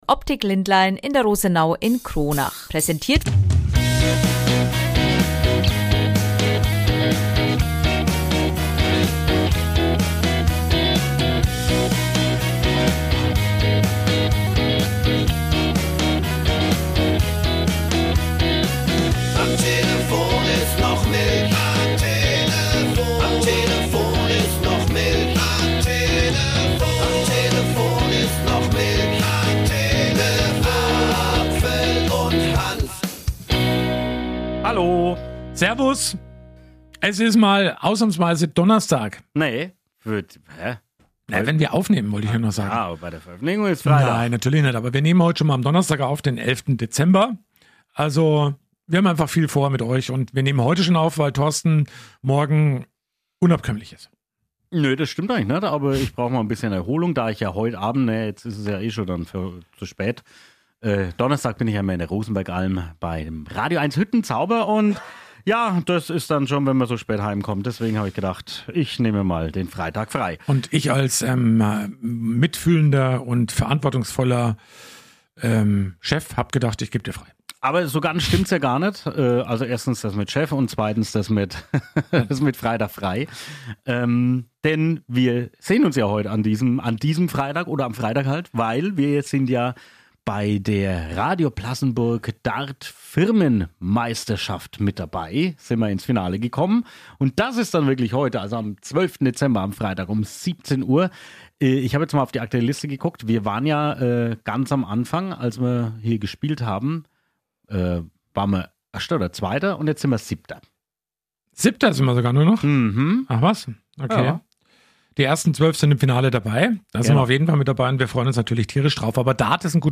Dazu gibt es viele Berichte und Interviews